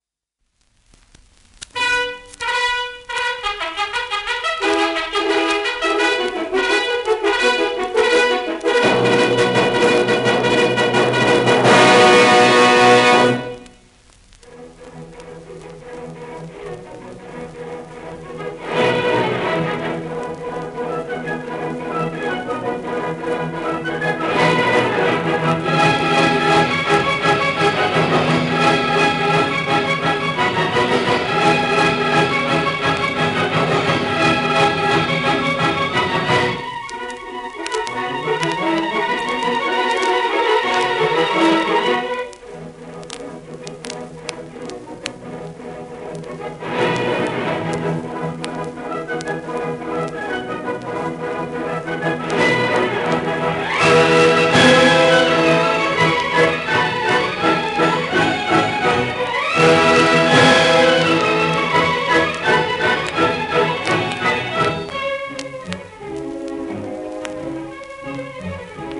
盤質B+A- *面擦れ,小キズ,アルバムに痛みあり